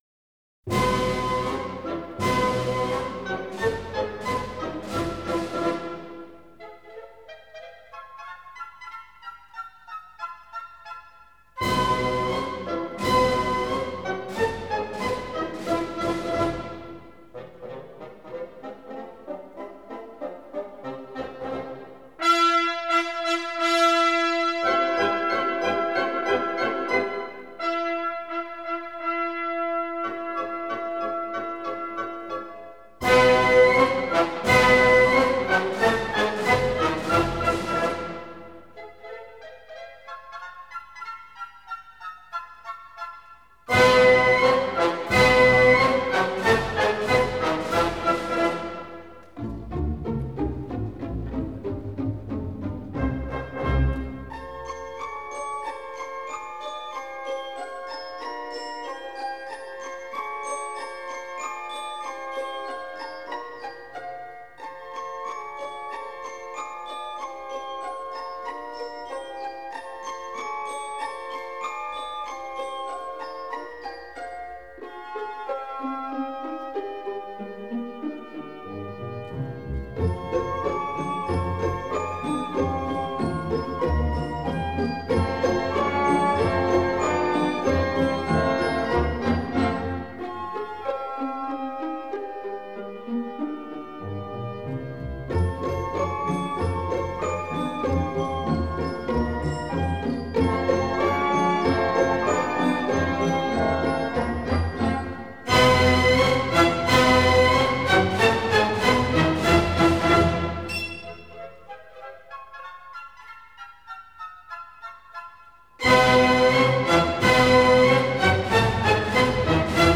Опера